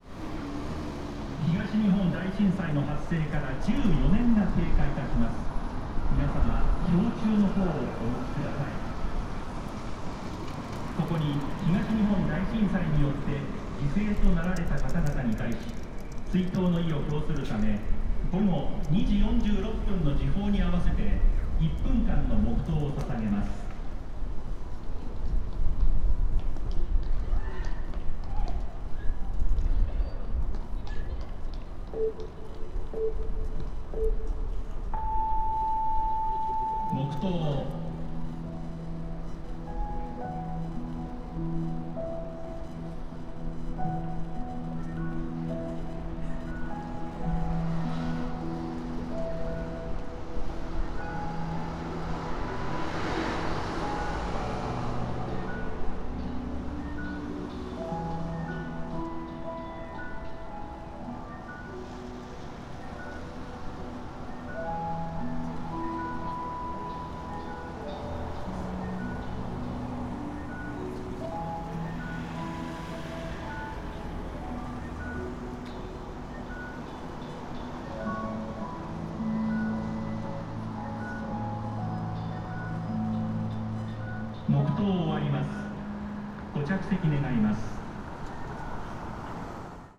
Fukushima Soundscape: Machi-naka Park